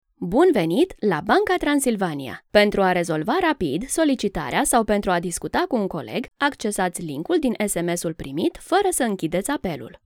Reliable, Friendly, Warm, Soft, Corporate
Telephony